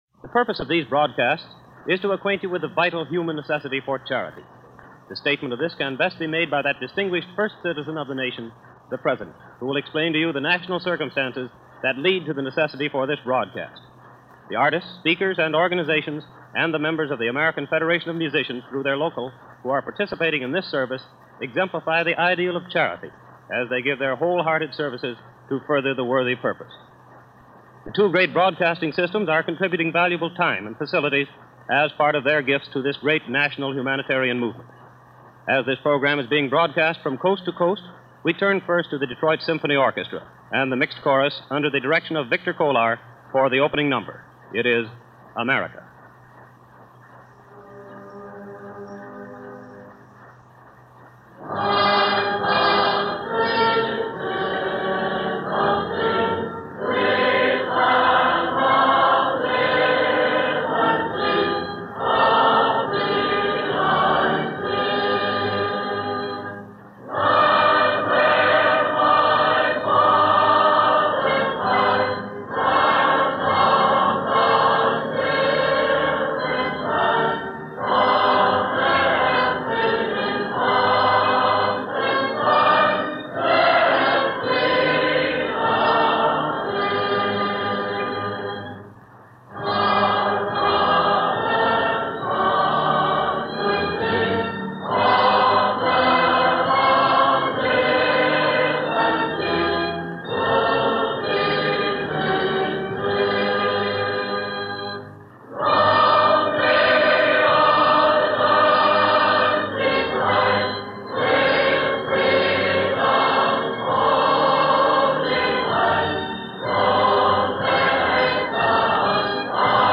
And during this 1935 Rally to celebrate the launch of the 1935 campaign, President Roosevelt was on hand to give a pep talk, bolster the confidence of the people and offer hope to those who needed it so badly.
The rallies were big affairs – this one not only featured President Roosevelt, but also members of local government (the program emanated from Detroit ) and the Detroit Symphony performed. Here is FDR’s portion.